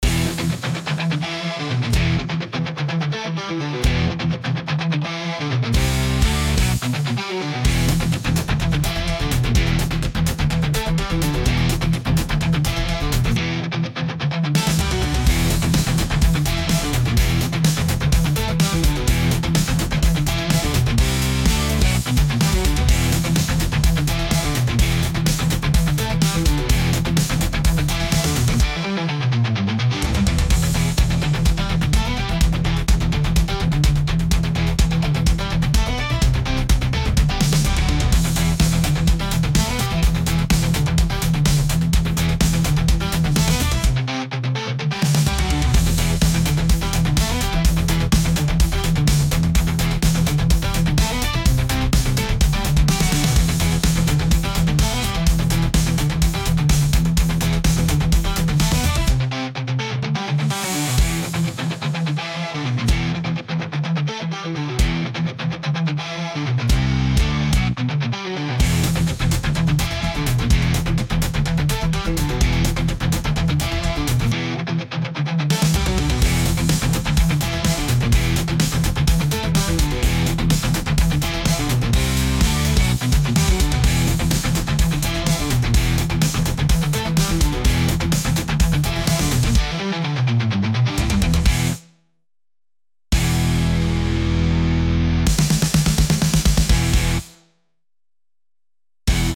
ゲームの戦闘音楽みたいなロック